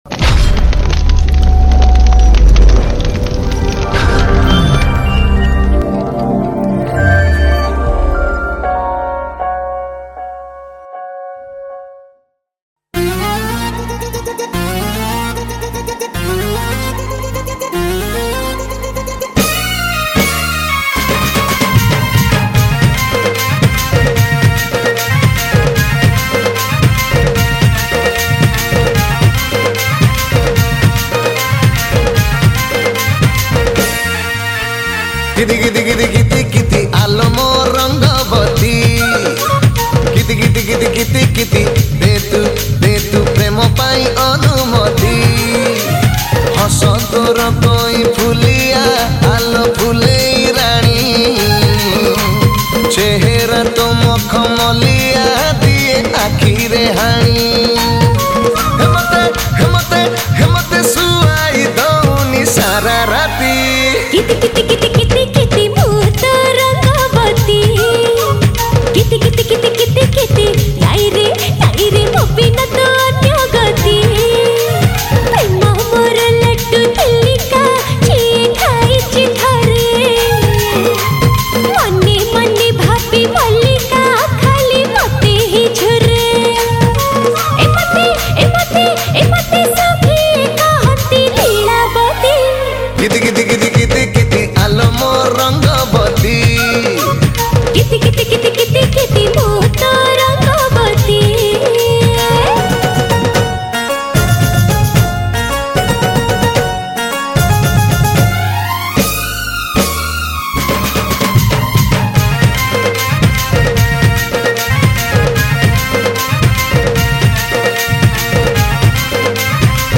Single Odia Album Song 2024 Songs Download
Dhol & Nisan